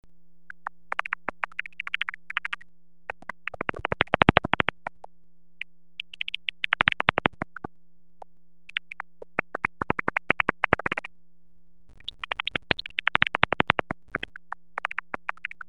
Netopýr nejmenší
Záznam echolokace v systému heterodyning
Silné echolokační signály mají největší hlasitost na frekvenci 50–57 kHz.